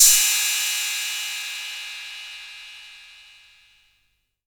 808CY_1_Tape.wav